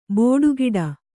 ♪ bōḍu giḍa